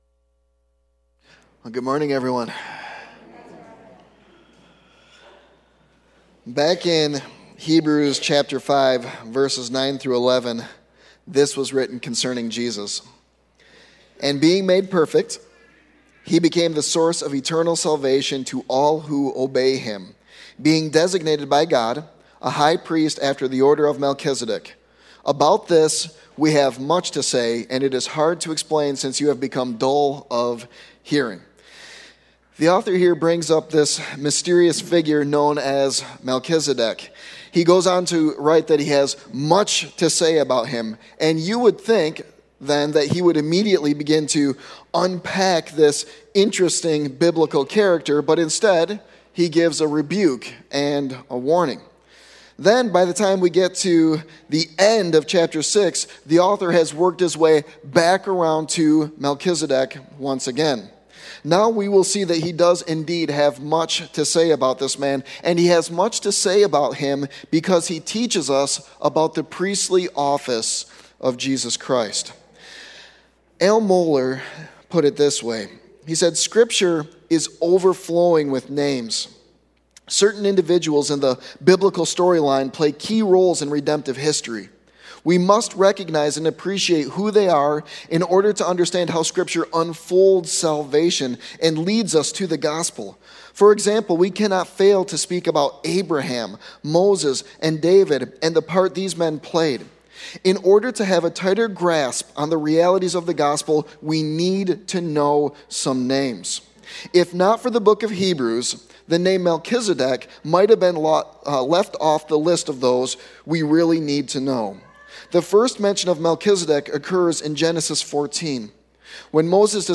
Sermon Text: Hebrews 7:1-19